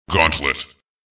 Play, download and share Quake 3 Gauntlet original sound button!!!!
quake-3-gauntlet.mp3